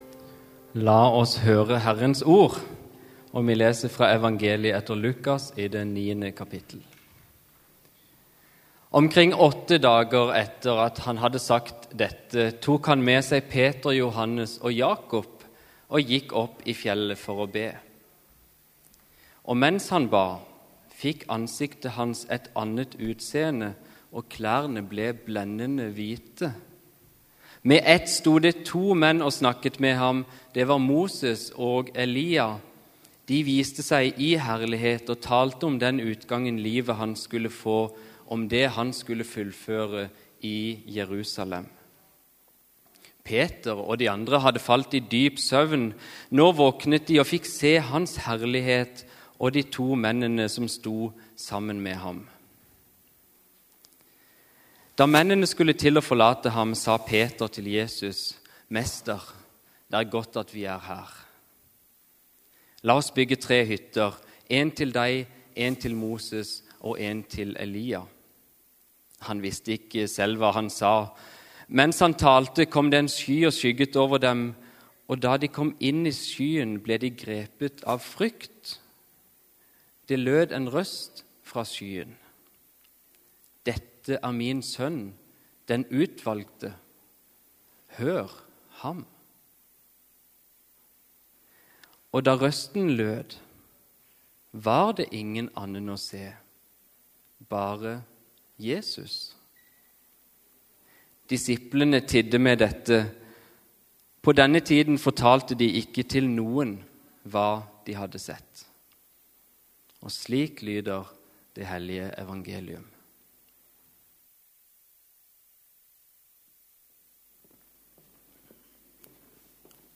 Gudstjeneste 20. februar 2022, - Kristi forklarelsesdag | Storsalen